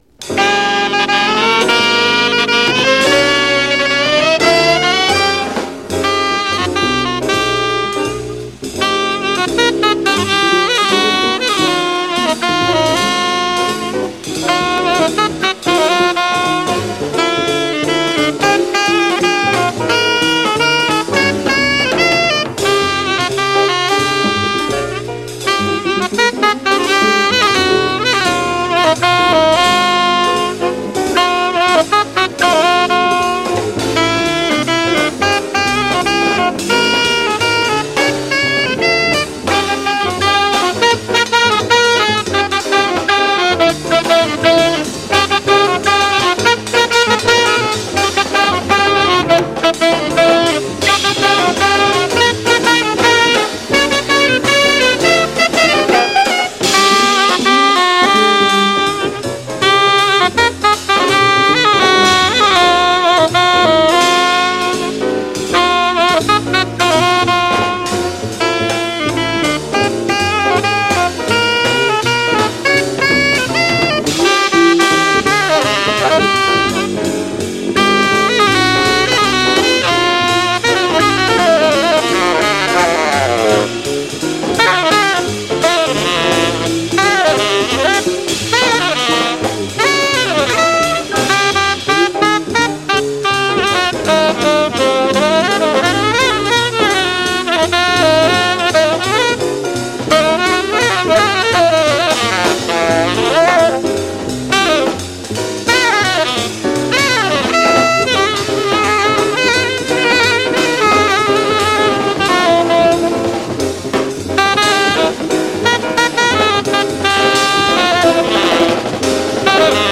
Vastly-overlooked Jazz treasure
two incredibly soulful players from Philly.
soprano tenor
alto
heavyweight quintet
piano
bass
drums
percussion